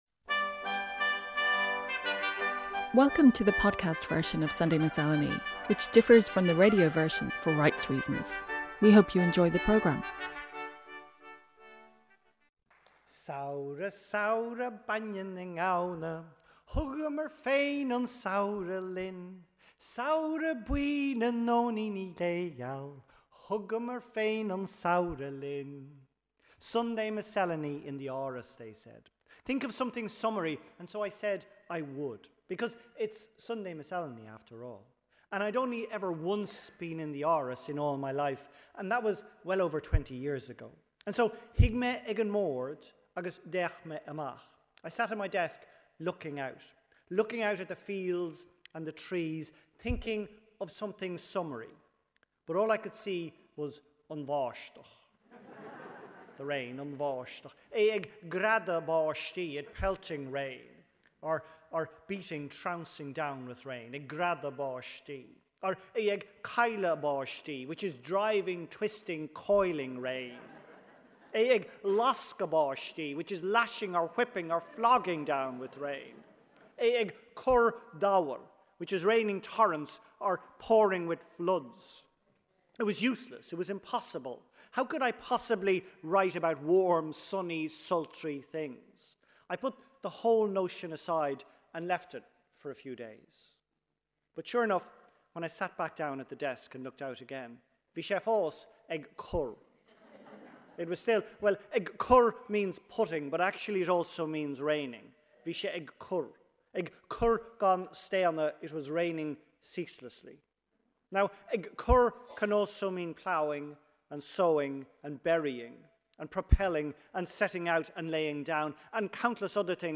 Radio essays, poems and complementary music, broadcast from Ireland on RTÉ Radio 1, Sunday mornings since 1968.